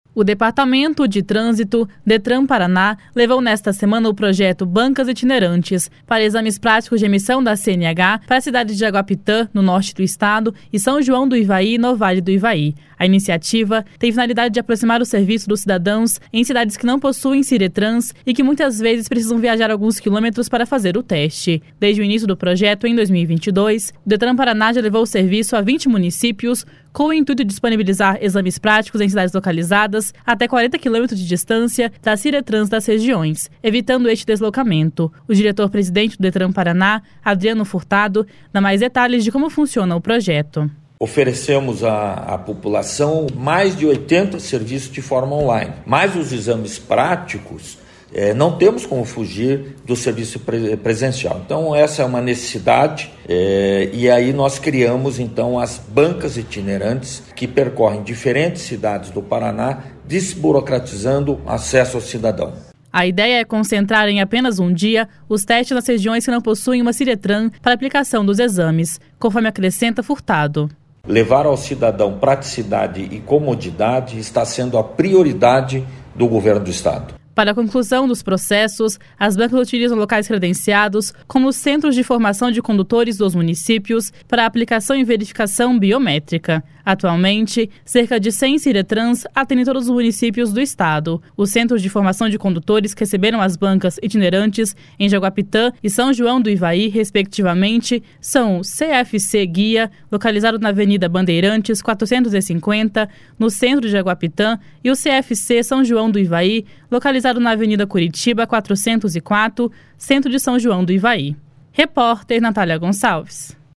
O diretor-presidente do Detran-PR, Adriano Furtado, dá mais detalhes de como funciona o projeto.